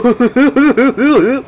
Goofy Laugh Sound Effect
Download a high-quality goofy laugh sound effect.
goofy-laugh.mp3